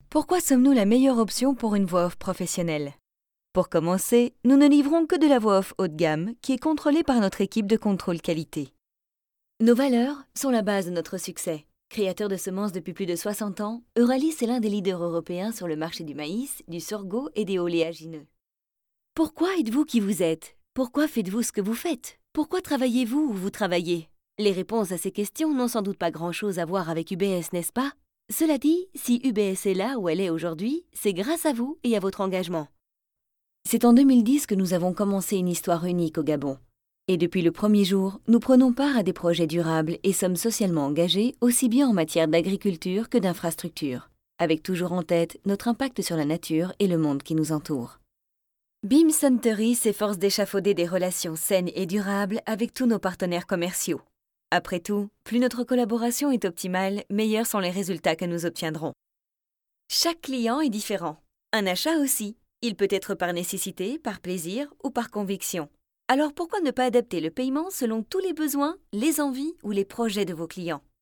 I like to modulate my voice according to the products.
Sprechprobe: Industrie (Muttersprache):
Professional actress without accent for more than 10 years!